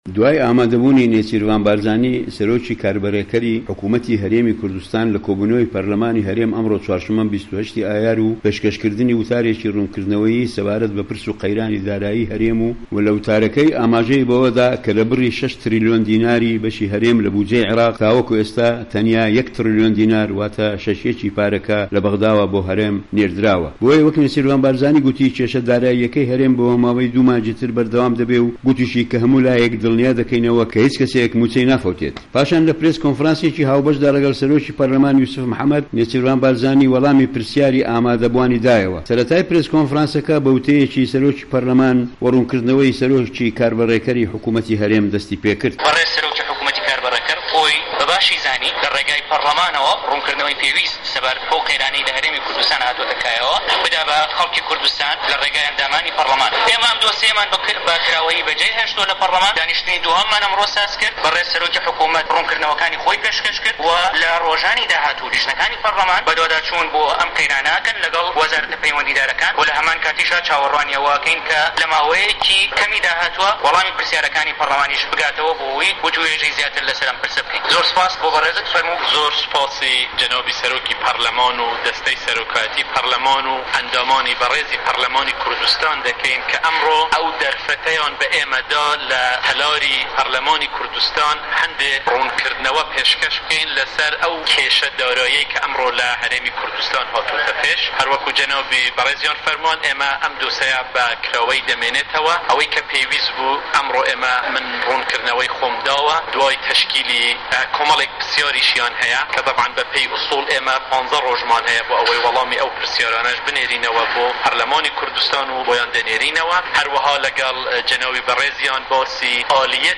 ڕاپـۆرتێـک له‌ باره‌ی وتاره‌که‌ی نێچیرڤان بارزانی له‌ په‌رله‌مانی هه‌رێمی کوردسـتانی عێراق